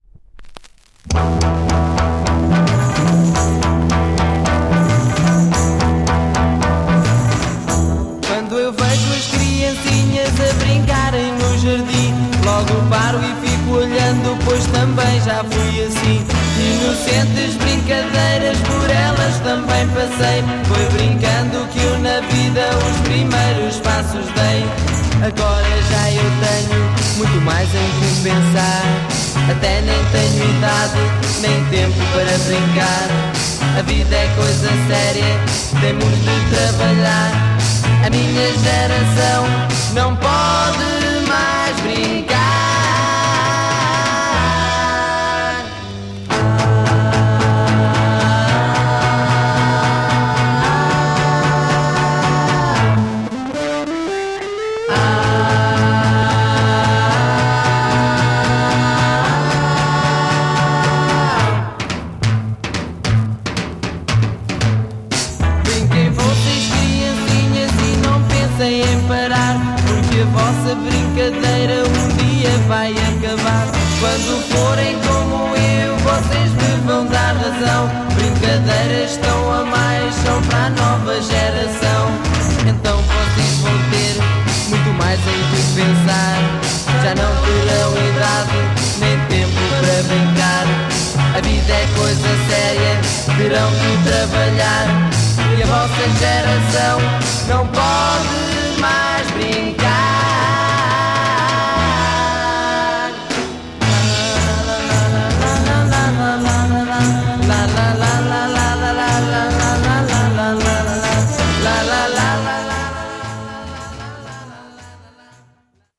Great Portuguese freakbeat Psych pop EP